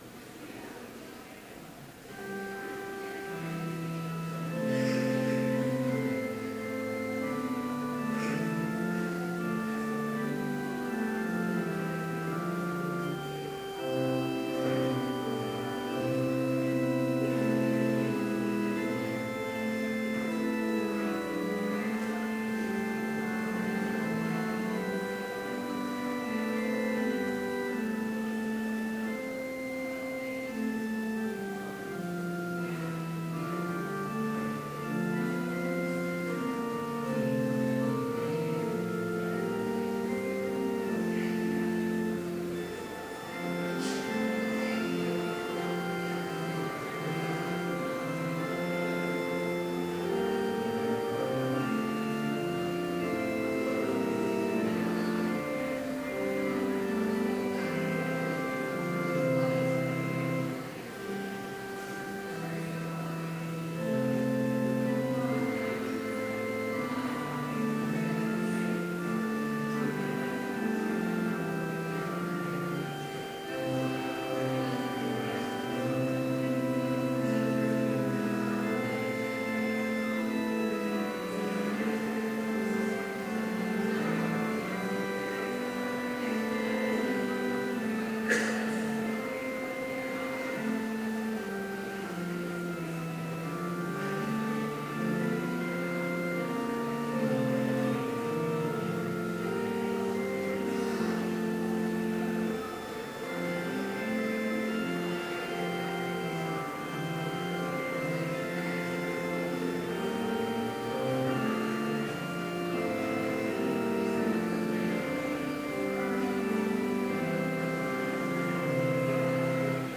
Complete service audio for Chapel - September 30, 2015